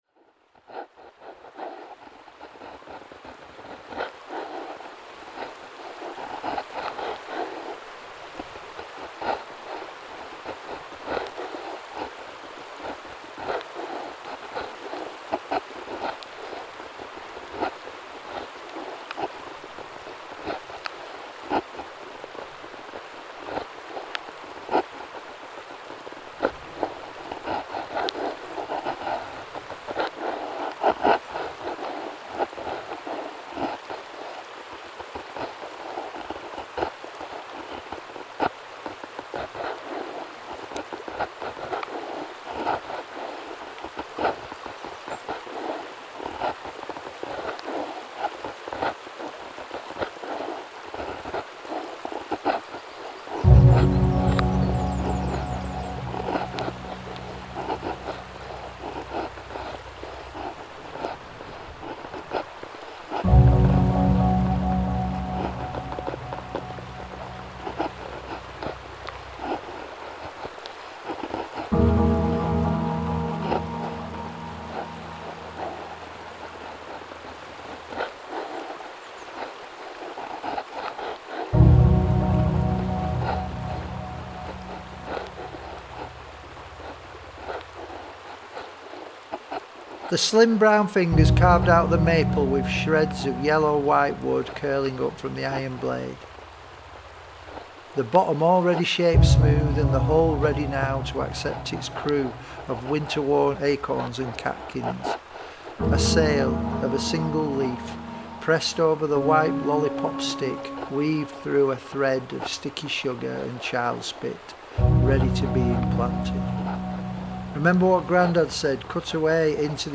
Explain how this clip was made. Chapter 2 'Before' - Soundscape. A Collaborative audio rendering of the Chapter 'Before'